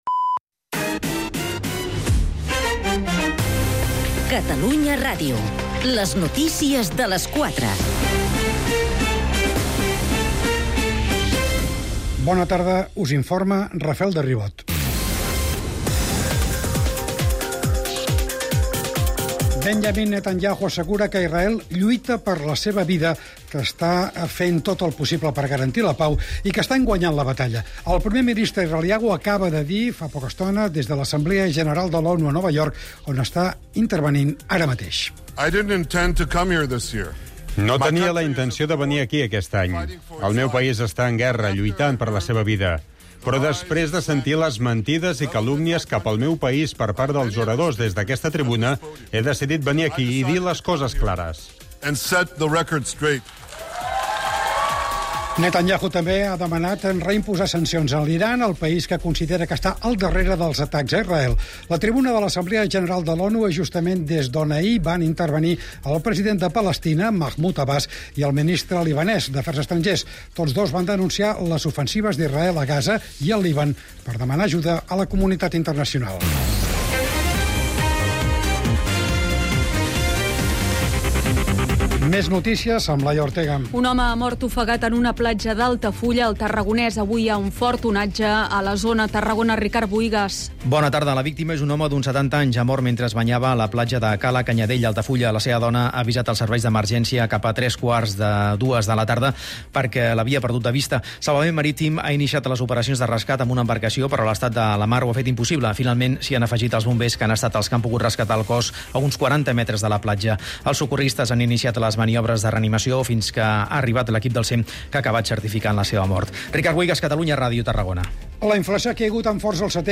Un programa que, amb un to proper i dists, repassa els temes que interessen, sobretot, al carrer. Una combinaci desacomplexada de temes molt diferents.